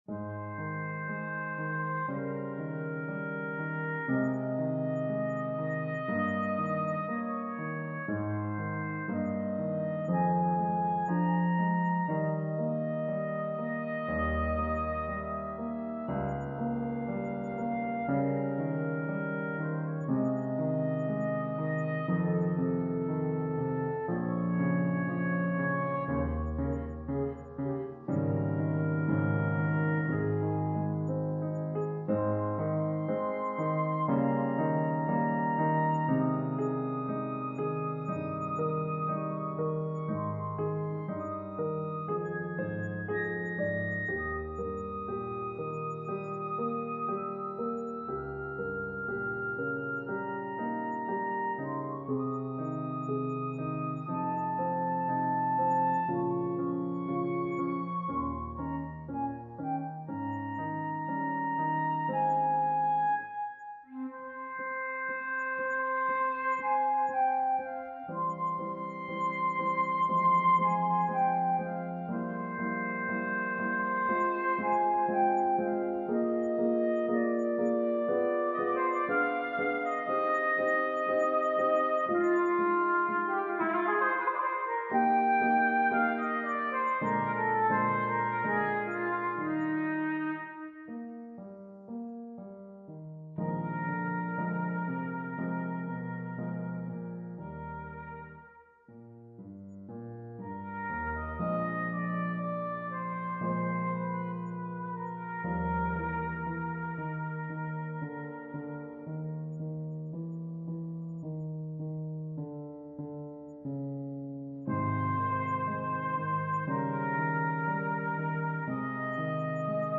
Voicing: Flute